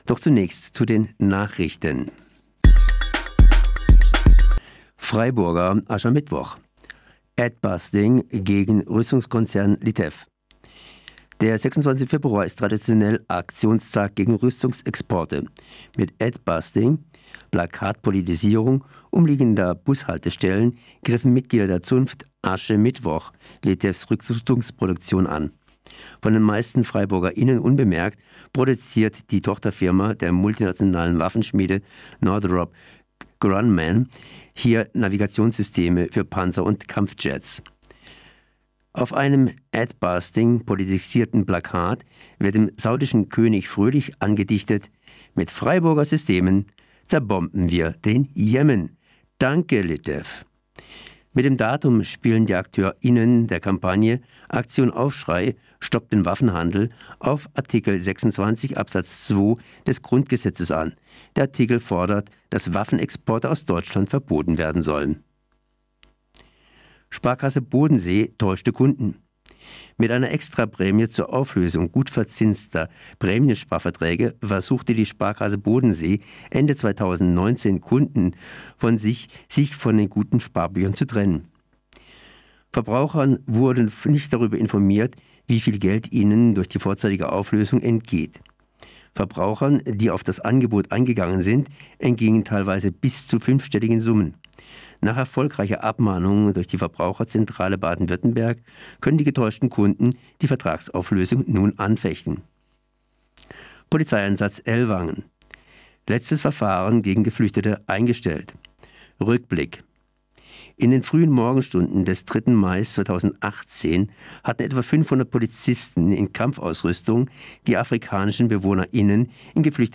Nachrichten:# Freiburger Asche-Mittwoch: Adbustings gegen Rüstungskonzern LITEF